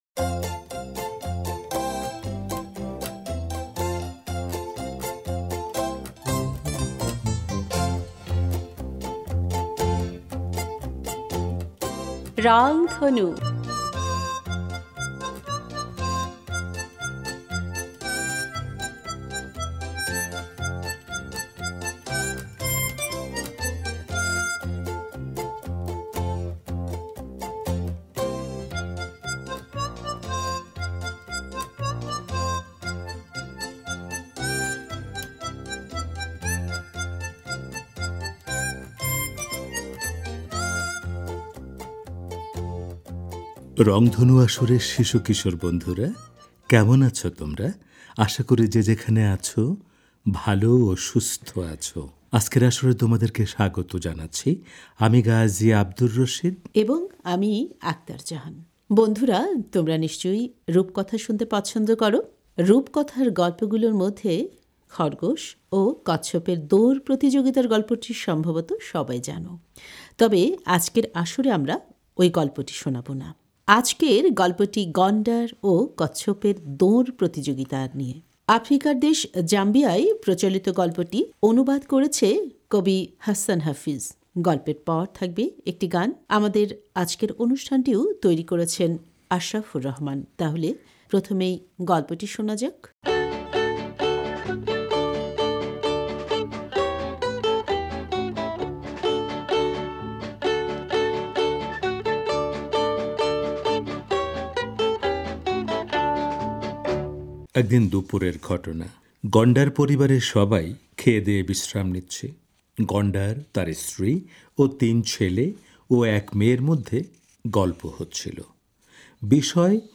গল্পের পর থাকবে একটি গান।